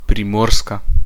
The Slovene Littoral, or simply Littoral (Slovene: Primorska, pronounced [pɾiˈmóːɾska]
Sl-Primorska.oga.mp3